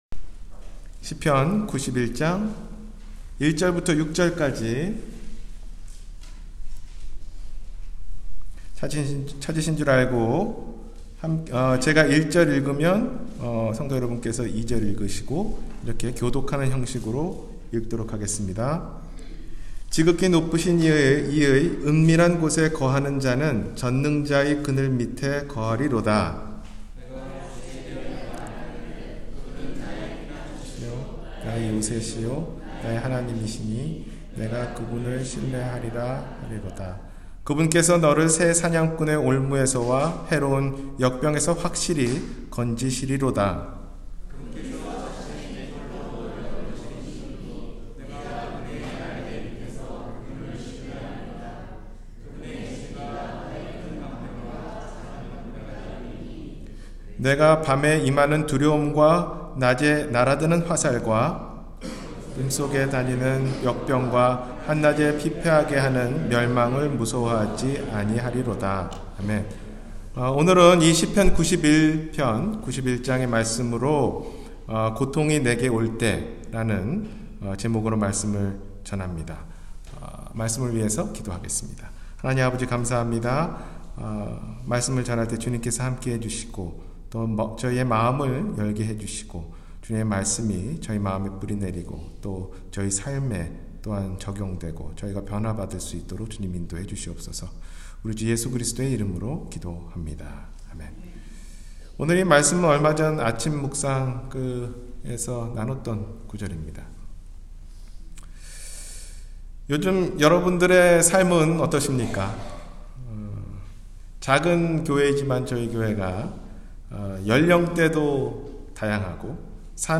고통이 내게 올 때 – 주일설교